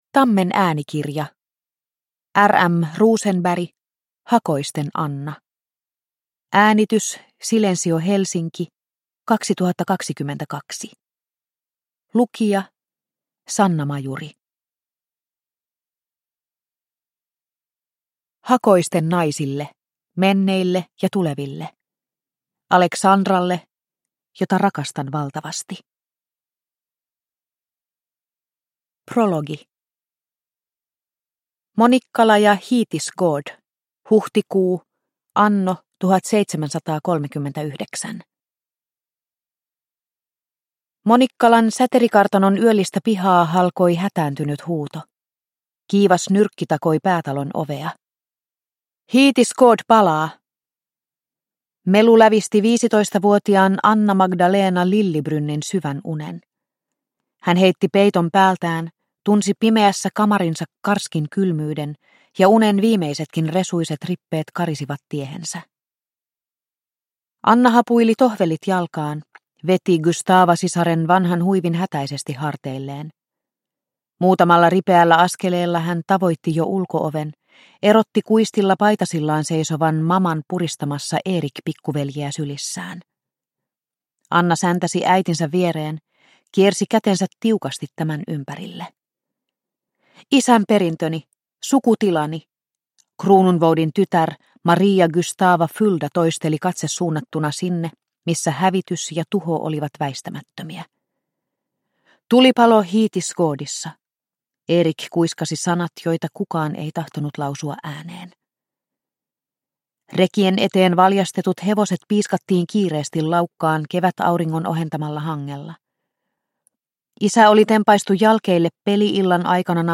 Hakoisten Anna – Ljudbok – Laddas ner